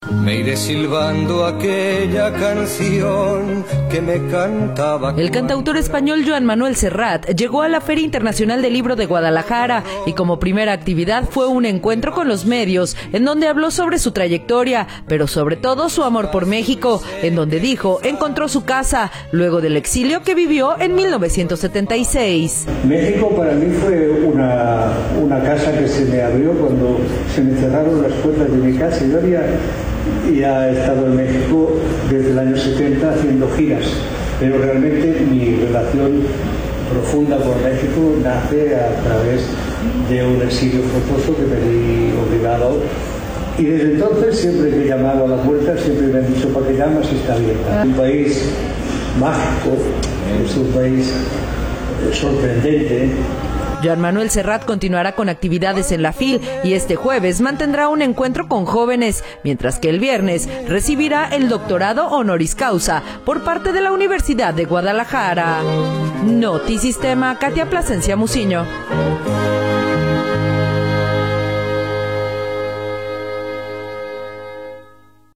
El cantautor español Joan Manuel Serrat llegó a la Feria Internacional del Libro de Guadalajara y como su primera actividad fue un encuentro con los medios en donde habló sobre su trayectoria, pero sobre todo su amor por México, en donde dijo […]